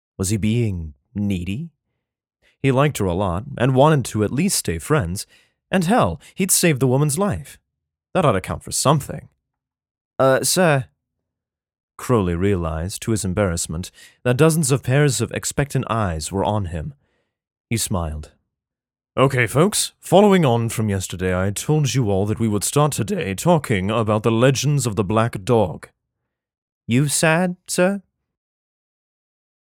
Classically Trained actor who specializes in Narration, commercial, video game, and animation voice over.
Sprechprobe: Sonstiges (Muttersprache):
Narration sample.mp3